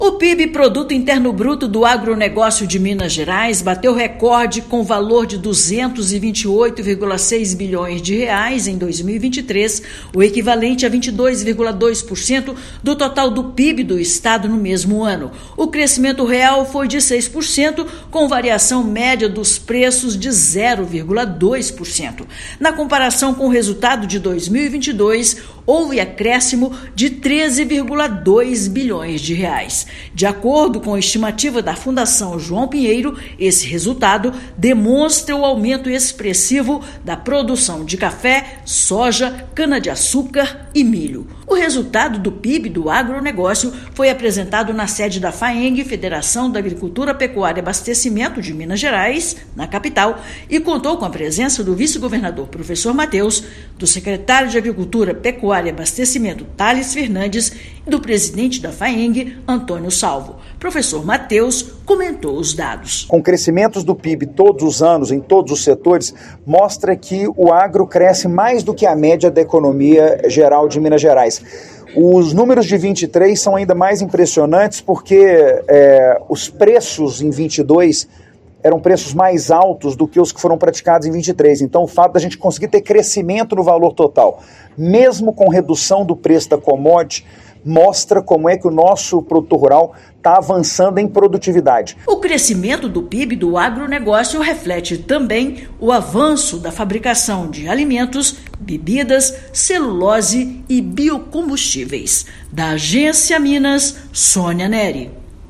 Números da Fundação João Pinheiro mostram que o setor representa 22,2% do PIB total do estado. Ouça matéria de rádio.